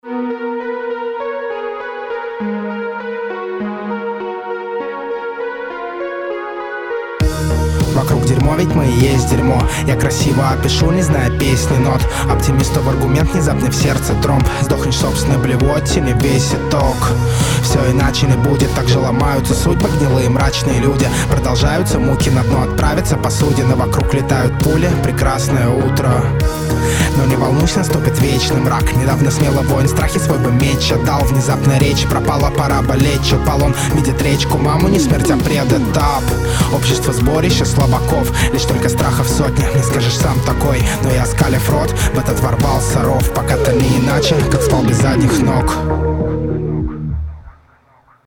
Голос серый, читка не ритмичная и не разнообразная, текст простой, ничем не выделяющийся
До боли мрачный трек. Я бы принял этот цинизм, но подано все приглушенным полушепотом, который не демонстрирует силы голоса